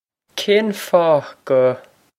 Pronunciation for how to say
Kayn faw guh
This is an approximate phonetic pronunciation of the phrase.